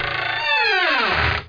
creak3.mp3